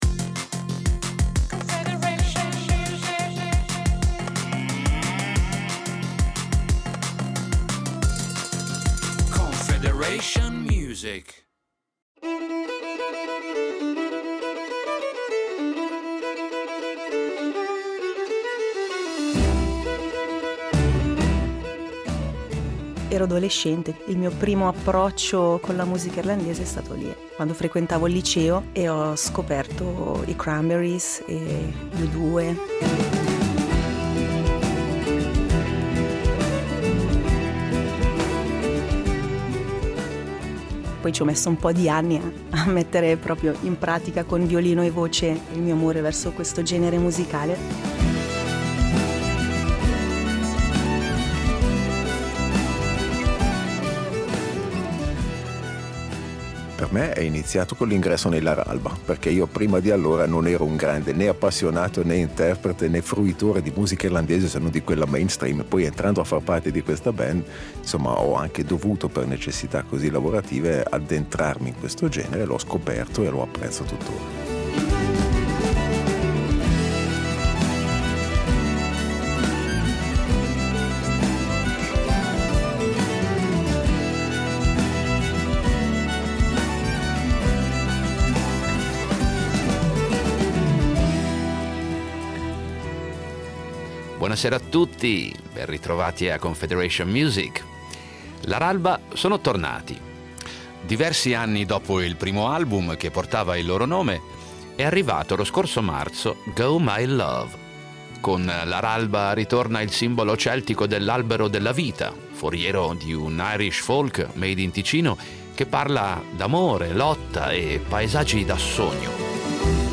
Musica folk Laralba